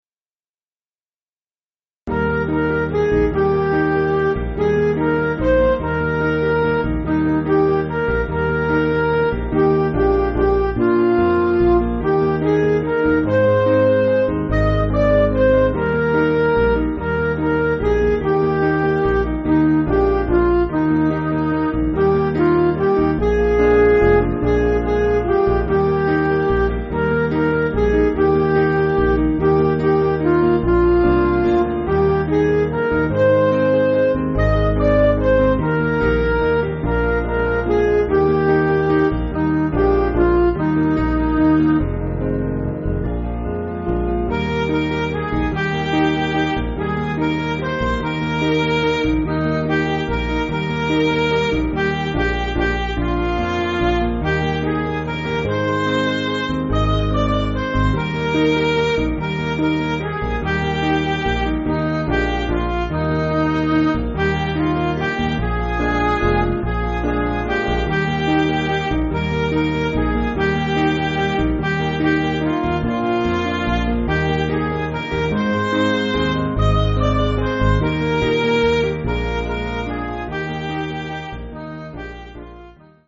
Piano & Instrumental
(CM)   4/Eb